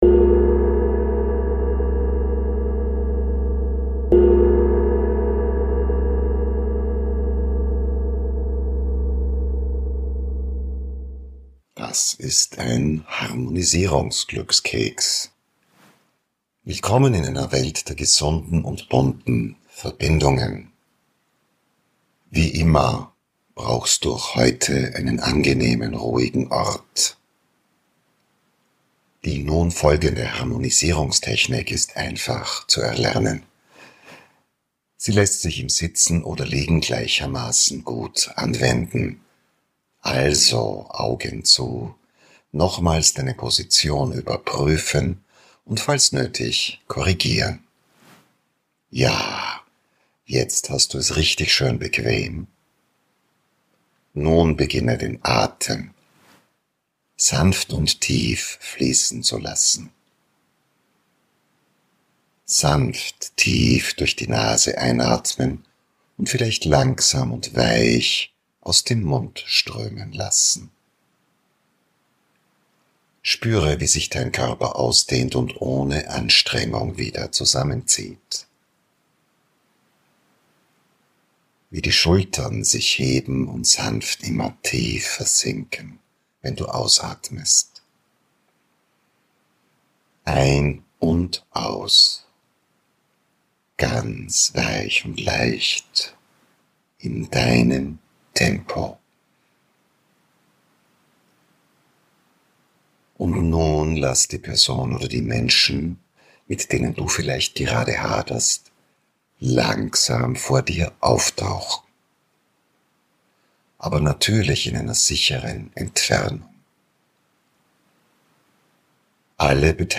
Eine Meditation zur Harmonie mit dir selbst und anderen.